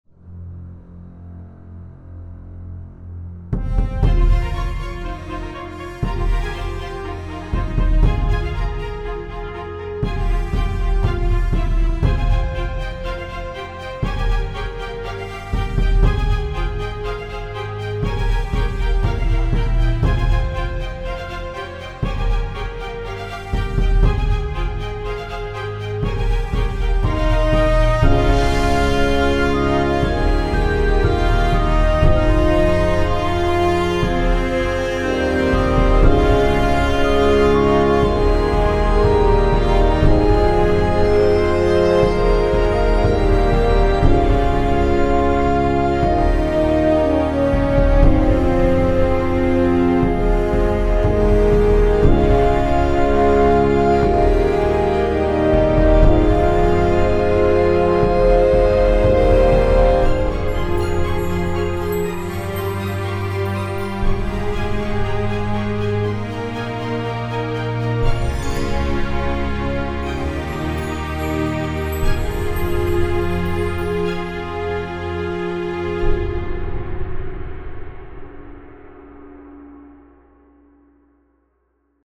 Sounds so professional!
Superbe, même si le galop est moins évident. 15.5/20